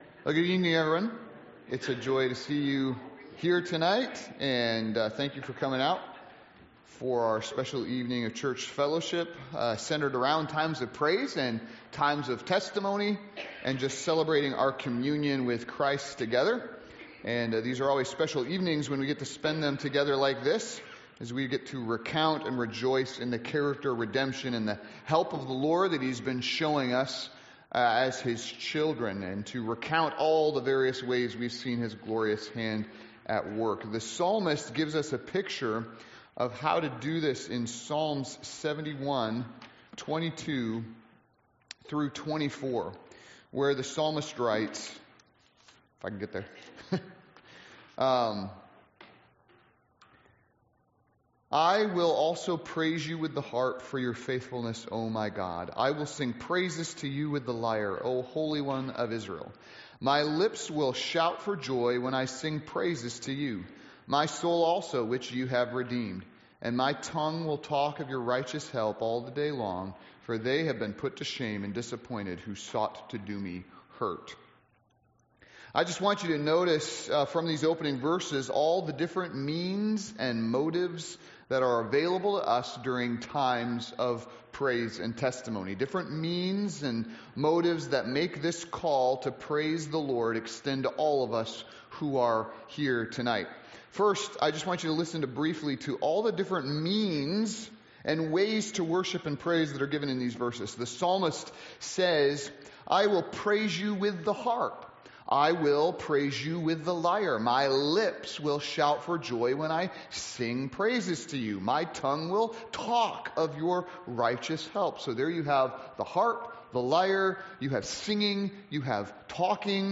Praise & Testimony Service